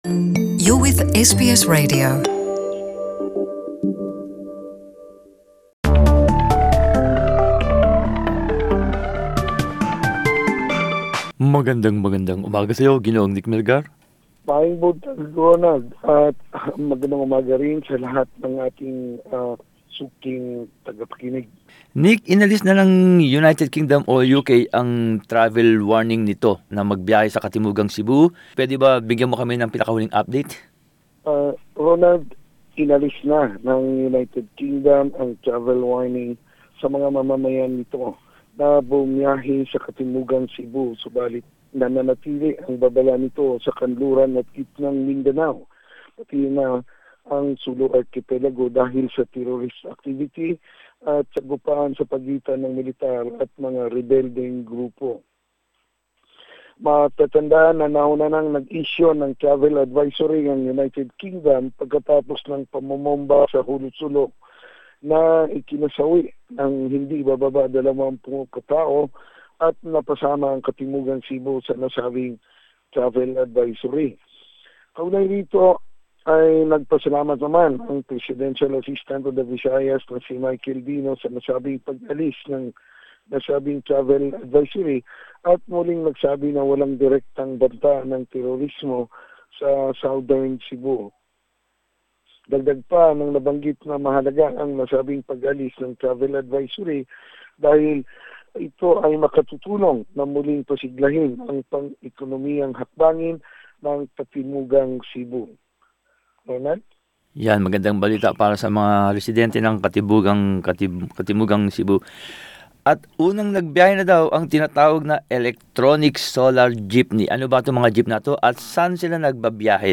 Balitang Bisayas.